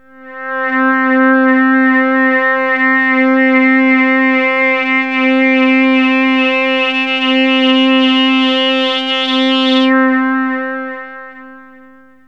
AMBIENT ATMOSPHERES-4 0006.wav